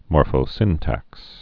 (môrfō-sĭntăks)